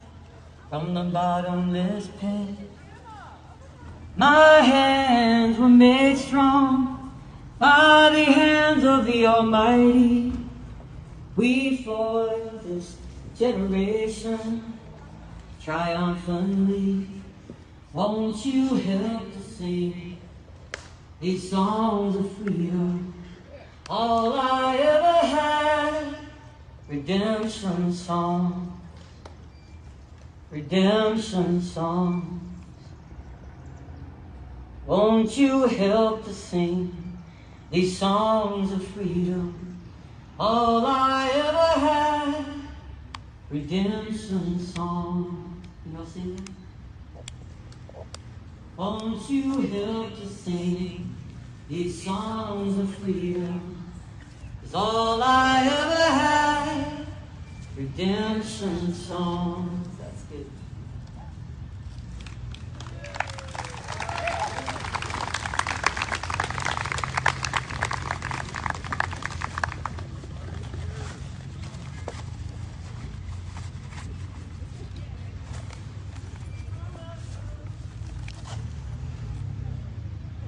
(captured from a facebook livestream)